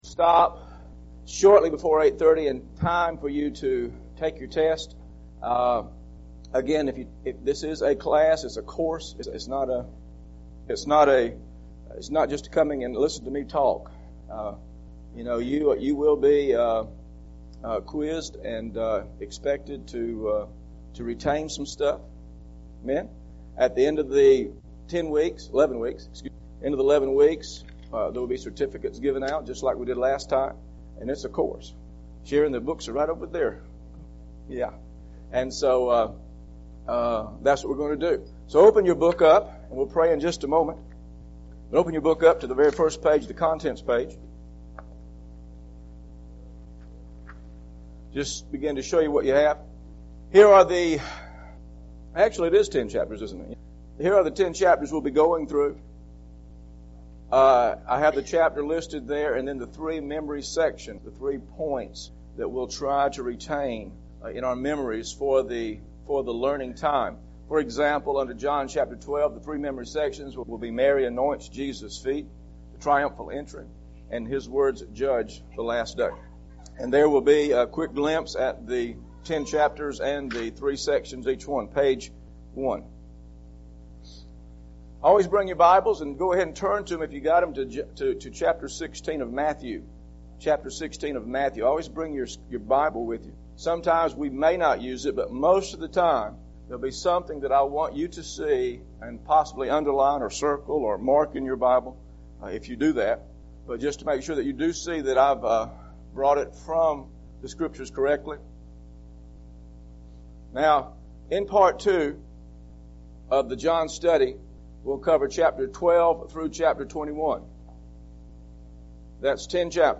Scriptures used in this lesson: John Chapter 12 John Chapter 12 John Chapter 12 Also see